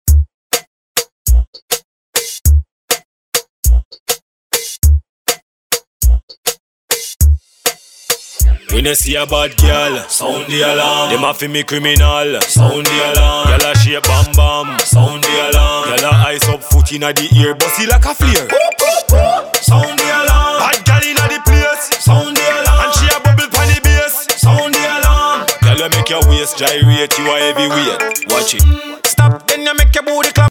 DJ and producer of tech house & house music
His style is unique and electrifying.